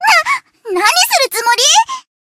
贡献 ） 分类:蔚蓝档案语音 协议:Copyright 您不可以覆盖此文件。
BA_V_Koharu_Formation_Select.ogg